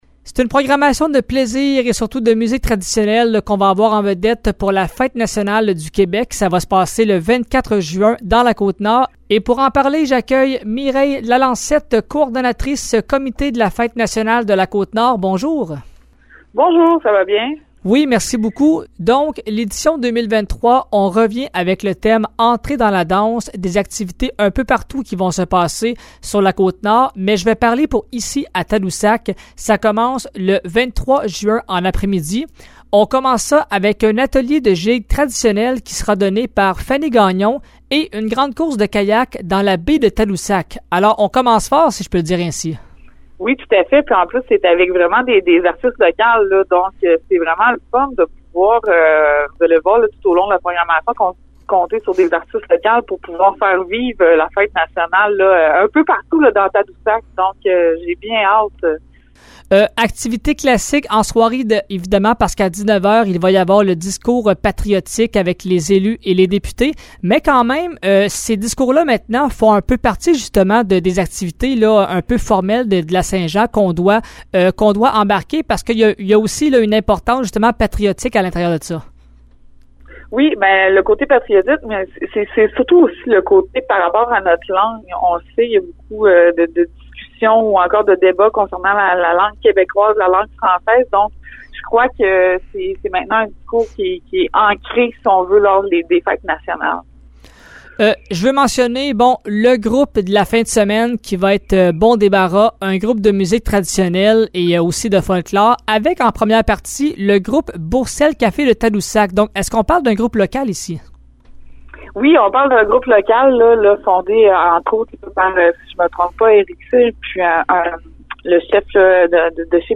Voici l’entrevue